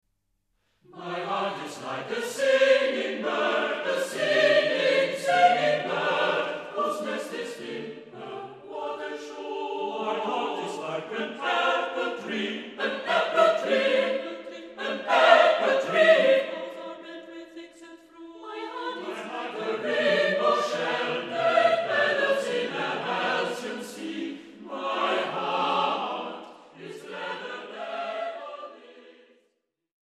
SATB (4 voix mixtes).